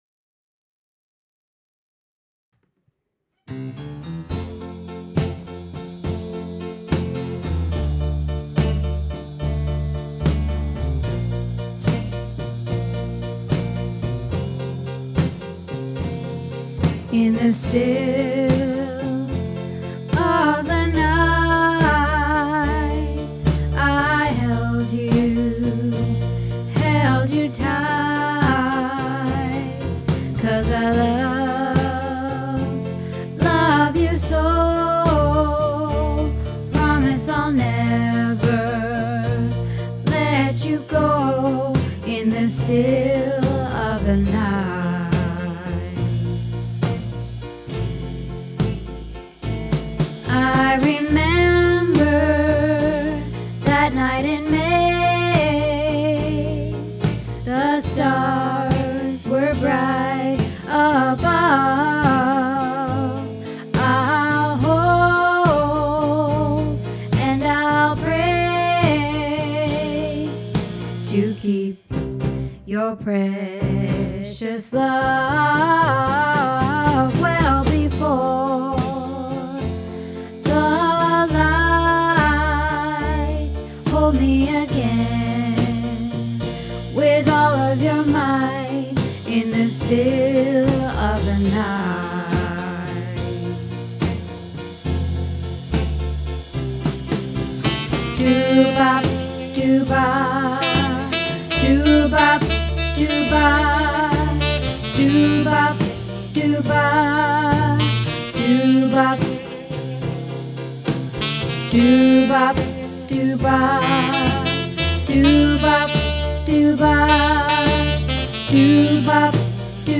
In the Still of the Night - Alto